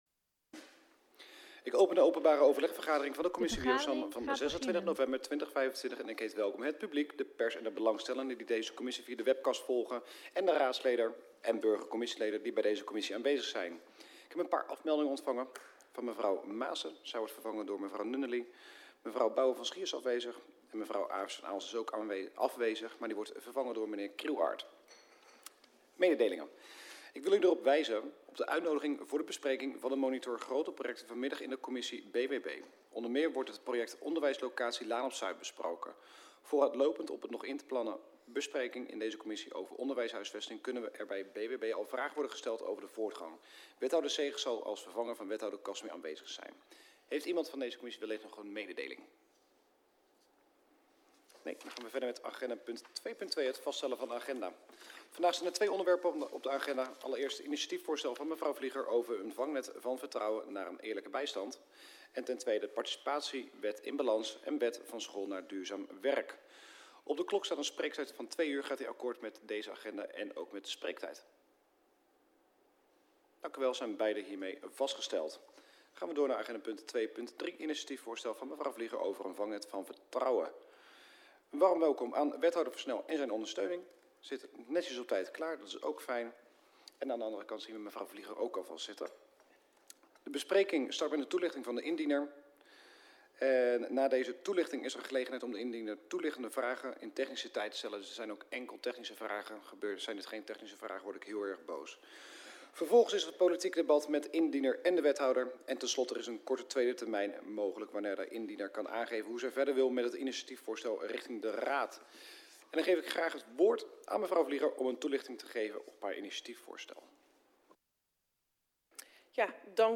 Locatie Raadzaal Voorzitter J.M.D. (Joey) de Waard Toelichting Het begin van de vergadering is door een technisch probleem op dit moment niet terug te kijken.